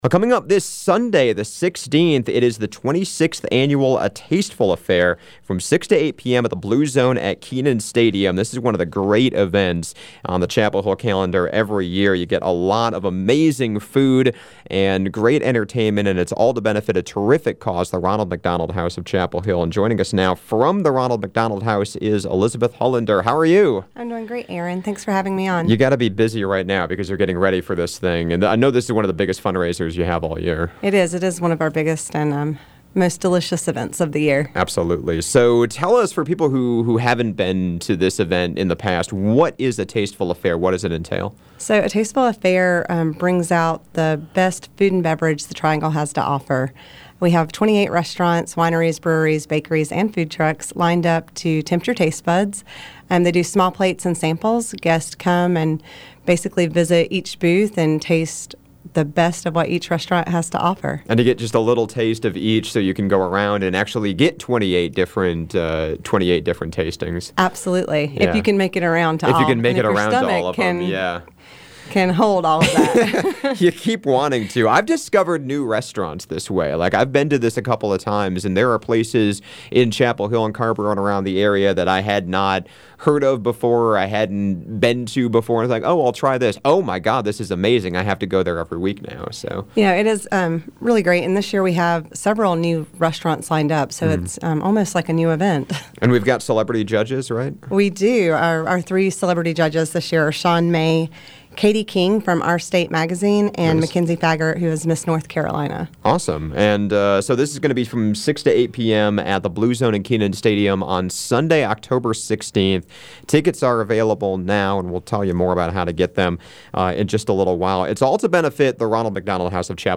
joined